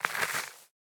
Minecraft Version Minecraft Version latest Latest Release | Latest Snapshot latest / assets / minecraft / sounds / block / composter / ready2.ogg Compare With Compare With Latest Release | Latest Snapshot